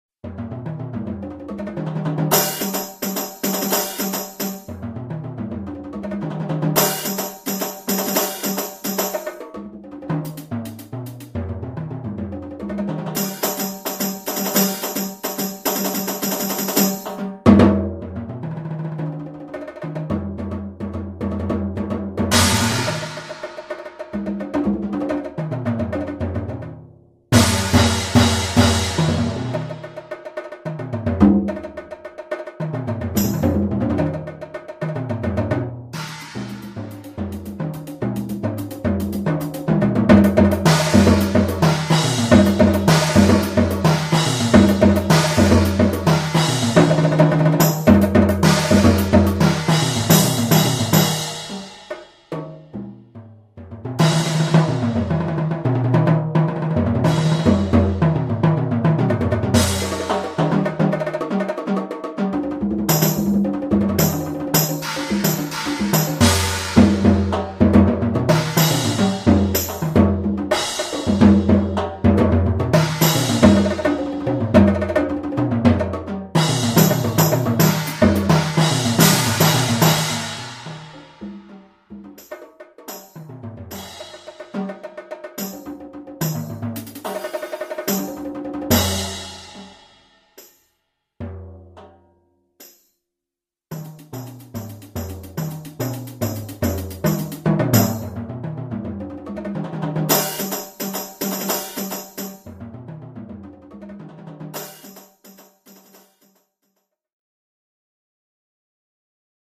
Voicing: Percussion Trio